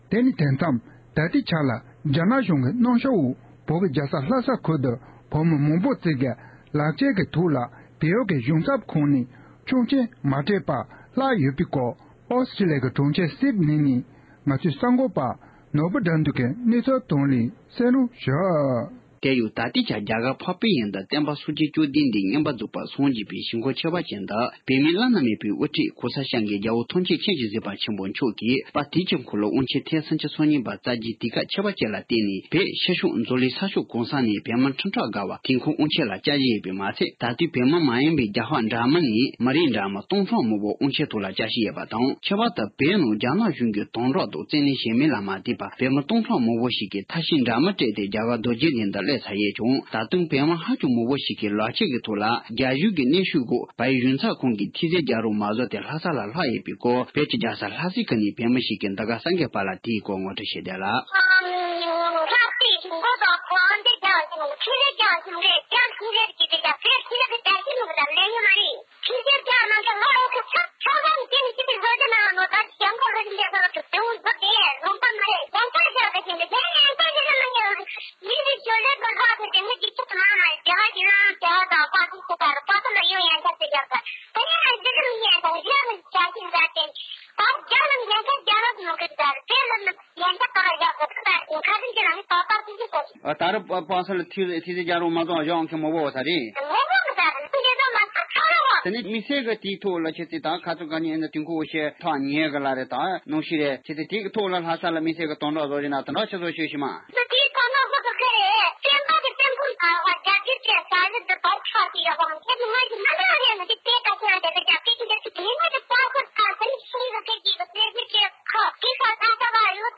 སྒྲ་ལྡན་གསར་འགྱུར། སྒྲ་ཕབ་ལེན།
བོད་ནས་གསུངས་མཁན་དེའི་སྐད་འགྱུར་བ་ཆེན་པོ་གཏང་རྐྱེན་གར་གསུངས་མི་ཤེས་འདུག